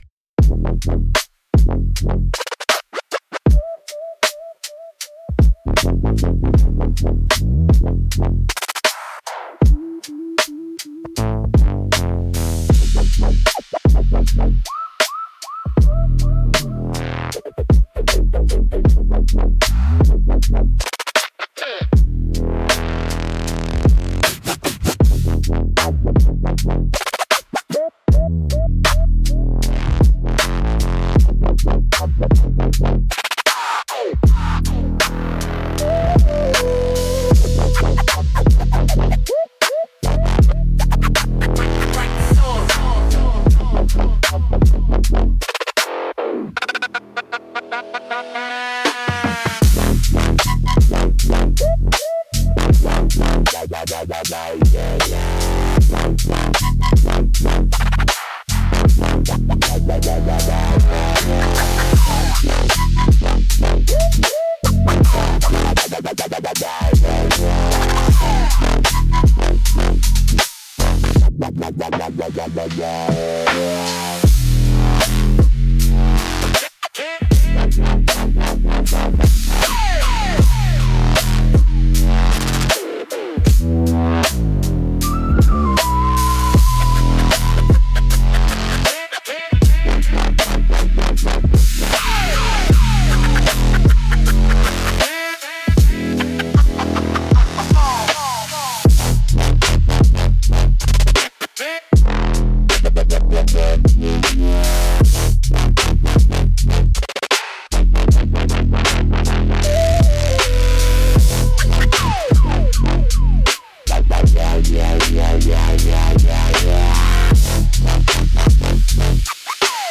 Instrumentals - Flicker in the Alley